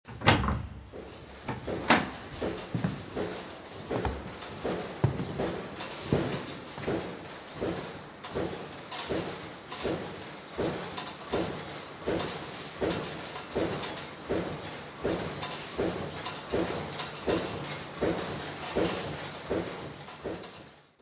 Angoise et epouvante : bruits et situations = Anguish and terror : noises and situations = Angustia y terror : ruidos y situaciones
Physical Description:  1 disco (CD) (ca. 63 min.) : stereo; 12 cm + folheto